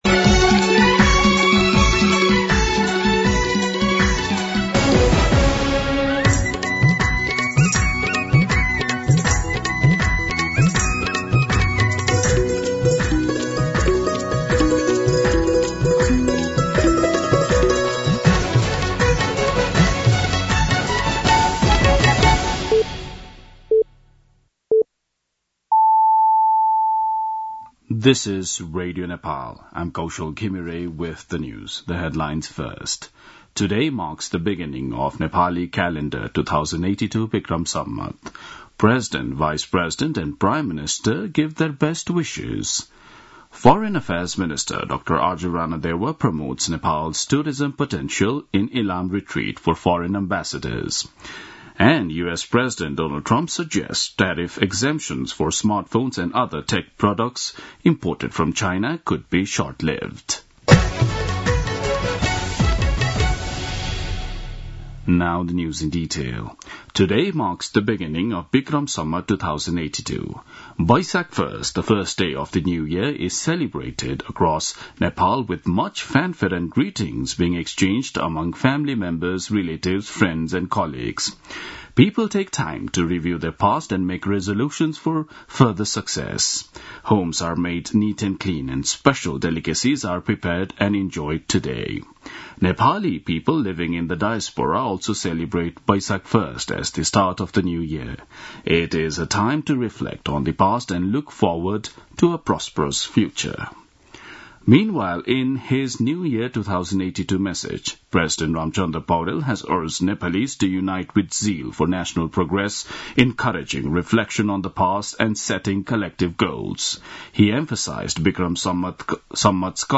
दिउँसो २ बजेको अङ्ग्रेजी समाचार : १ वैशाख , २०८२
2pm-English-news.mp3